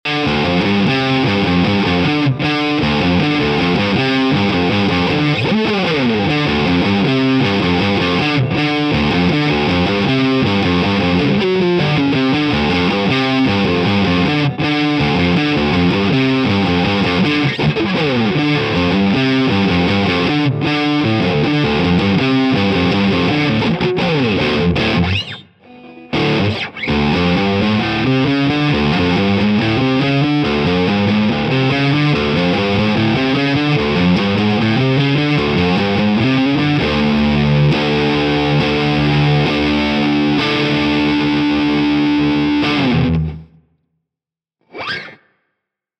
Am Start sind wie gesagt EMG 81 an der Bridge und EMG 60 am Hals. Während der Hals noch geht, ist der 81er hier m.E. Alienspucke ins Auge.^^ Beiden ist eigen, dass sie null dynamisch sind, man achte auf das Ende von Freedom, ich habe am Ende voll reingehauen, es klingt alles gleich laut, als hätte man den Waves M2 benutzt. Amp ist der Neural DSP Tom Morello im Default Setting - bei allen exakt das gleiche.